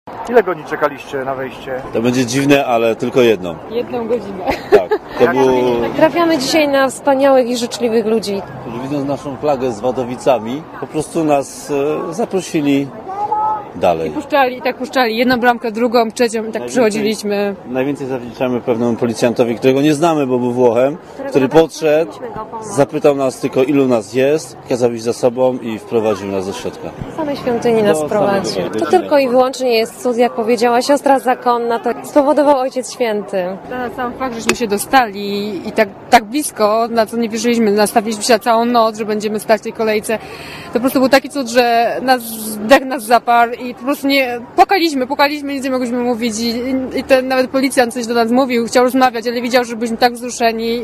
Pomagali nam wszyscy - opowiadali wzruszeni wadowiczanie reporterowi Radia ZET.